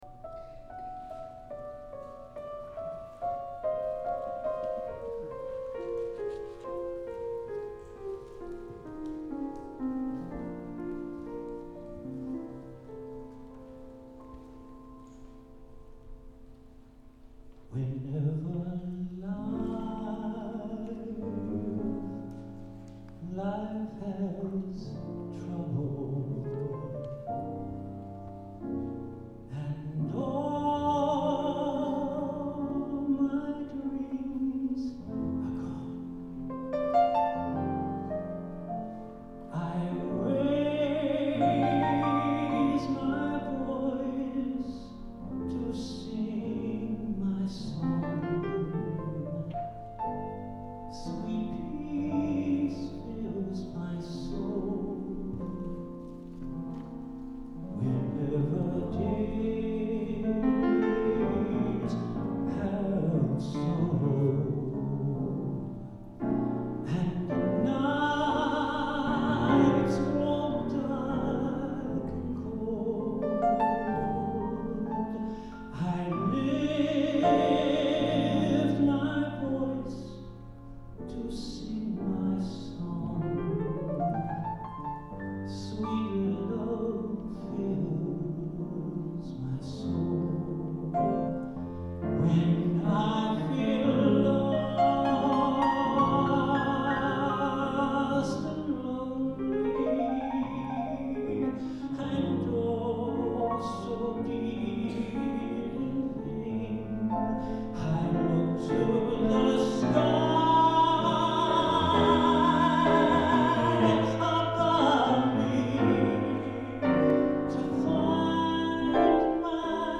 for tenor solo and choir ensemble, piano
A unison gospel setting